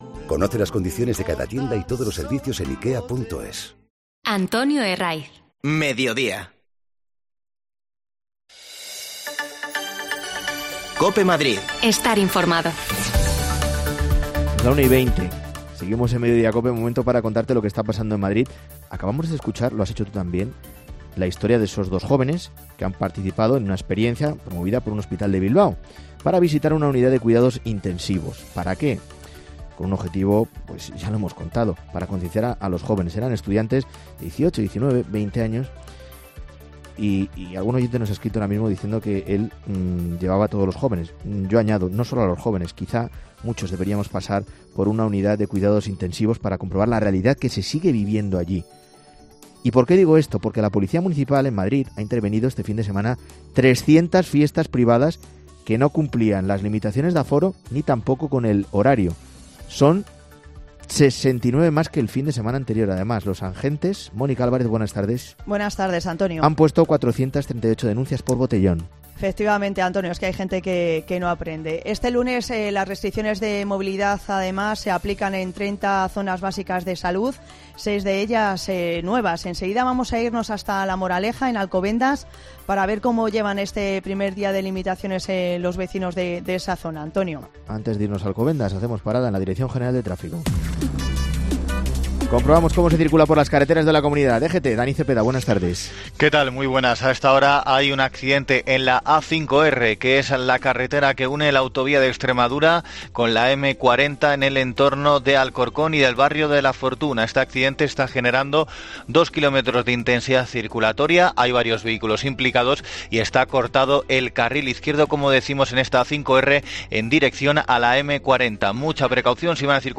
AUDIO: La Moraleja es una de las areas de salud que desde hoy tienen restricciones. Vamos a conocer qué opinan sus vecinos